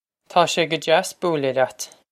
Pronunciation for how to say
Taw shay guh jass boo-lah lyat.
This is an approximate phonetic pronunciation of the phrase.
This comes straight from our Bitesize Irish online course of Bitesize lessons.